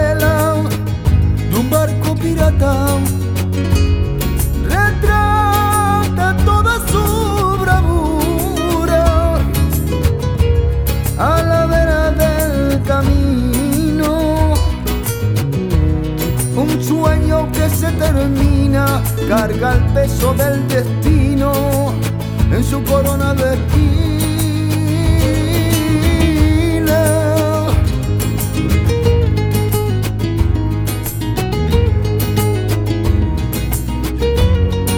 Música tropical Latin